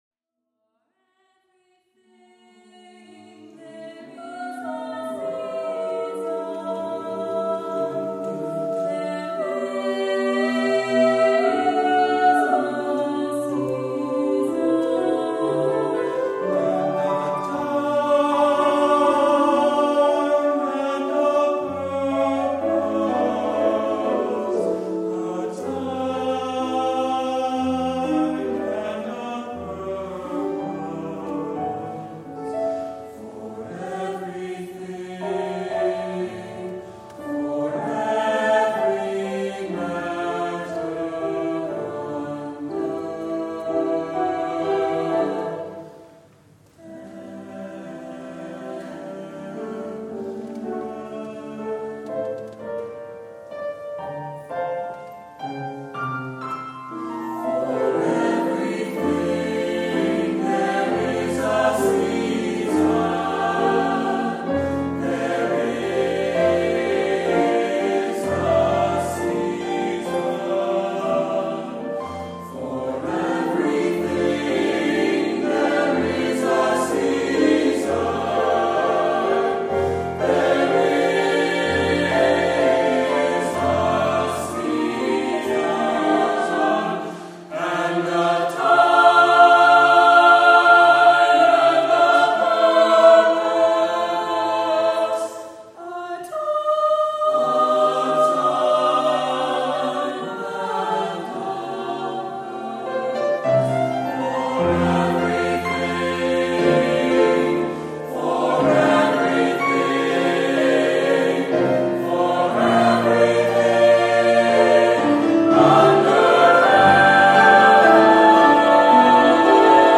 SATB/piano